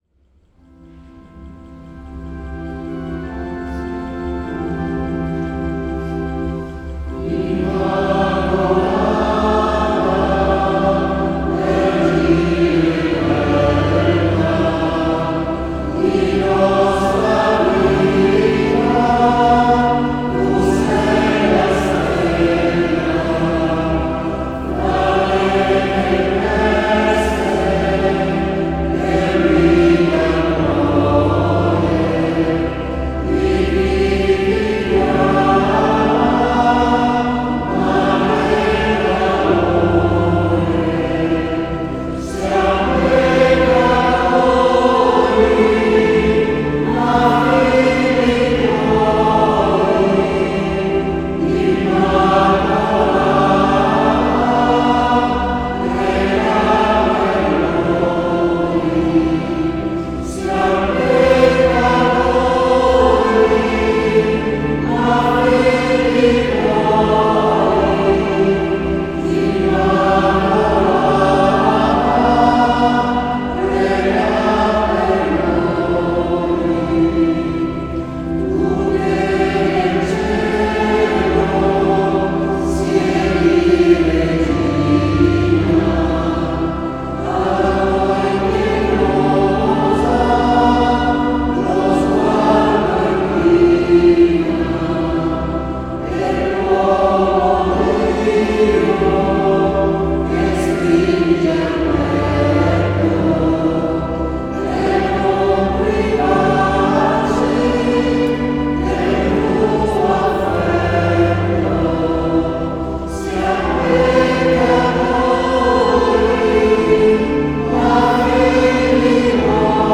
SCHOLA CANTORUM Sedico (Belluno)
Tradizionale
Sedico 25_03_2025